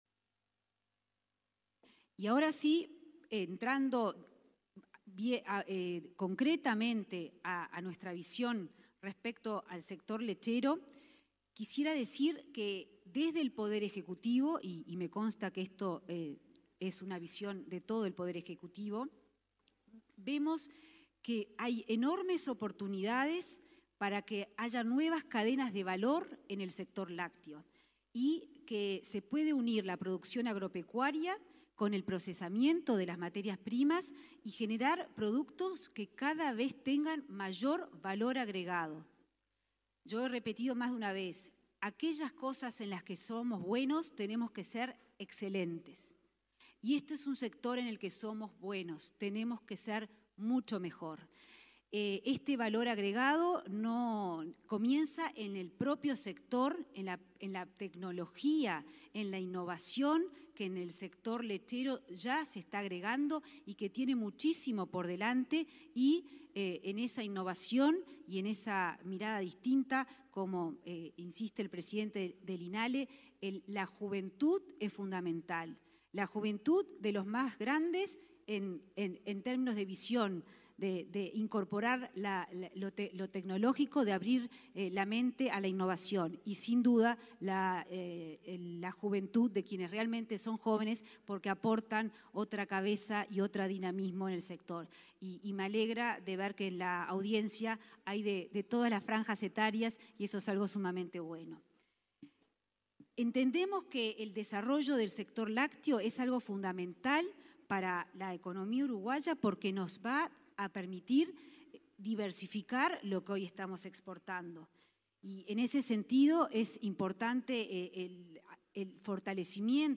Palabras de autoridades en encuentro con sector lechero en la Expo Prado
Palabras de autoridades en encuentro con sector lechero en la Expo Prado 16/09/2021 Compartir Facebook X Copiar enlace WhatsApp LinkedIn La ministra de Economía, Azucena Arbeleche; el presidente del Banco República, Salvador Ferrer, y el subsecretario de Ganadería, Juan Ignacio Buffa, participaron de un encuentro con gremiales lecheras, este jueves 16, en la Expo Prado.